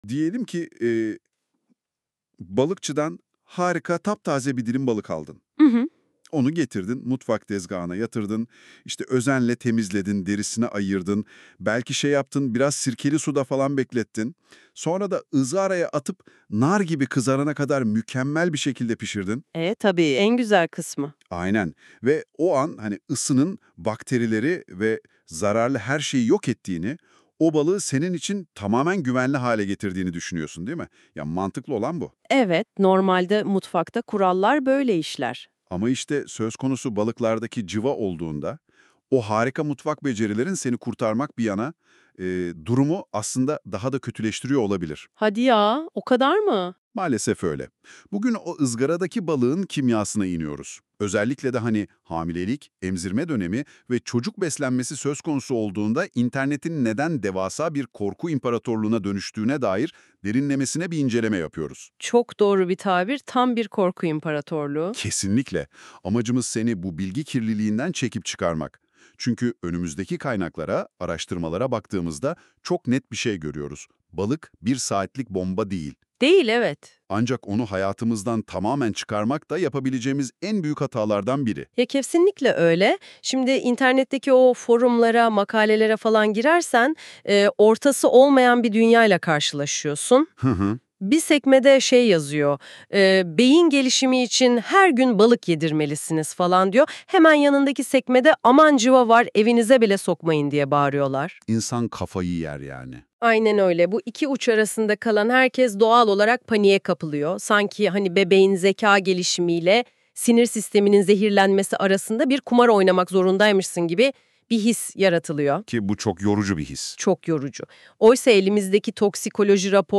Bu yazı hakkında AI Podcast dinleyin by NotebookLM MP3 İndir Yasal Uyarı: Bu yazı genel bilgilendirme amaçlıdır; bireysel sağlık durumunuzda hekiminiz/çocuğunuzun pediatristi ve yerel balık tüketim uyarıları önceliklidir.